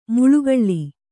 ♪ muḷugaḷḷi